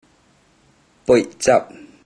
Click each Romanised Teochew word to listen to how the Teochew word is pronounced.
poid01zab1 (variant: poi51zab1)